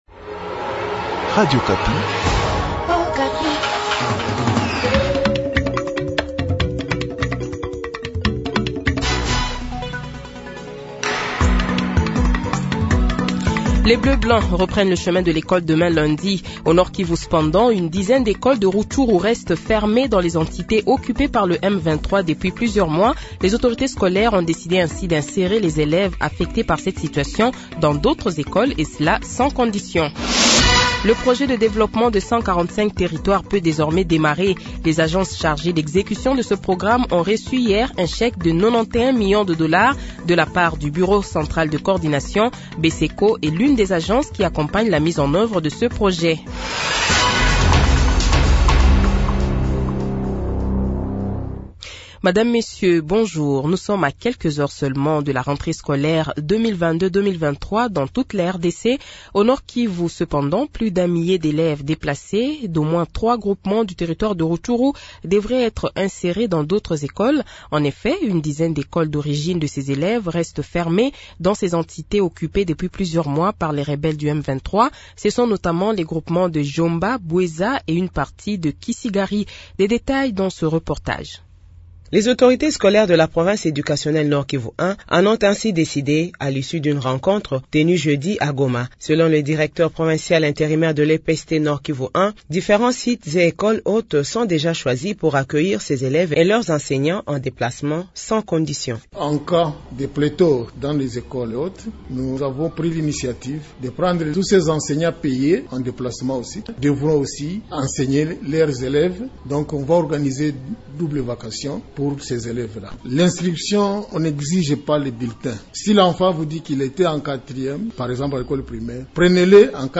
Journal Midi
Le Journal de 12h, 04 Septembre 2022 :